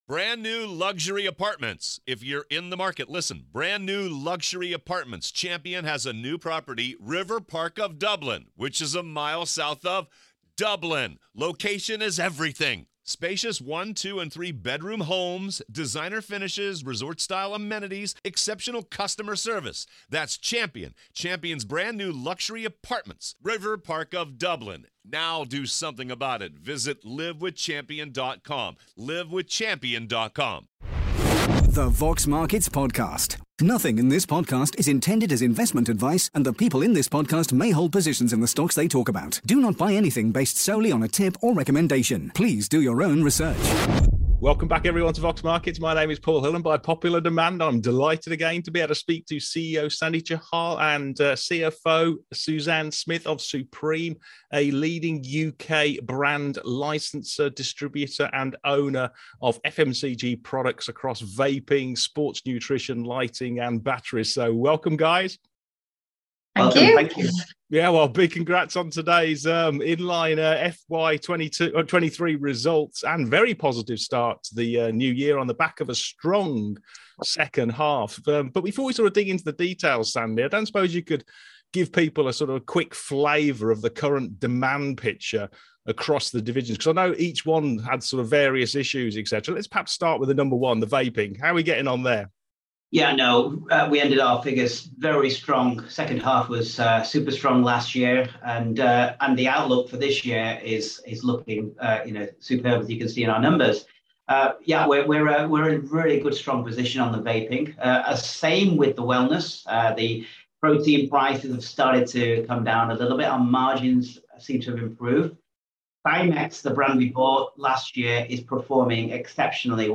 The Vox Markets Podcast / Q&A
In this interview